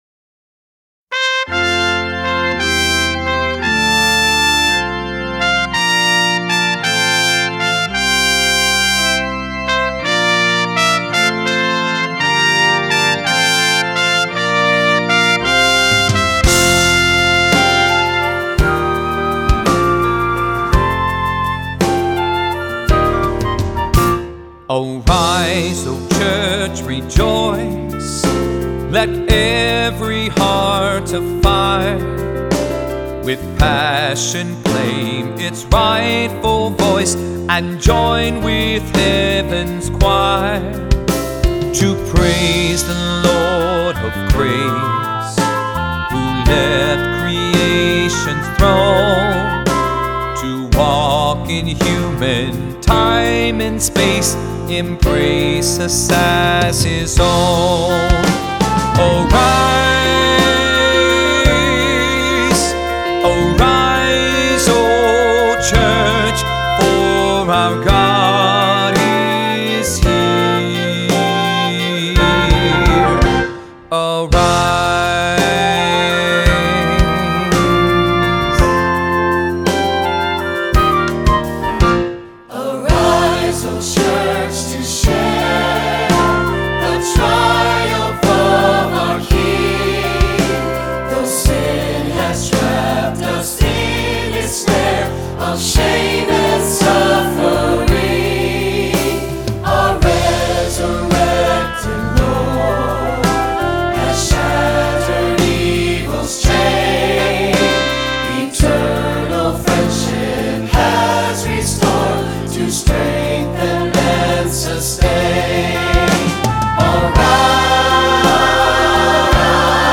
Voicing: Three-part equal; Cantor; Assembly